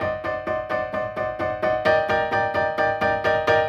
Index of /musicradar/gangster-sting-samples/130bpm Loops
GS_Piano_130-C1.wav